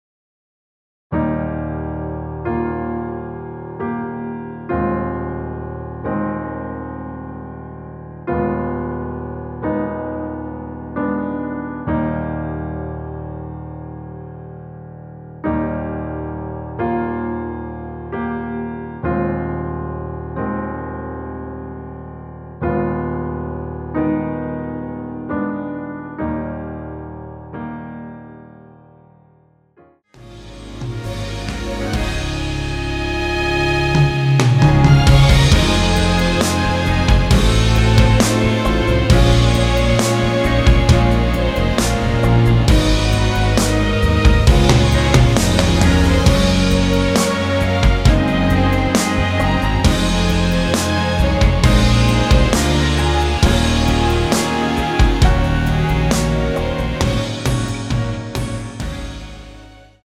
MR 입니다.
Eb
앞부분30초, 뒷부분30초씩 편집해서 올려 드리고 있습니다.
중간에 음이 끈어지고 다시 나오는 이유는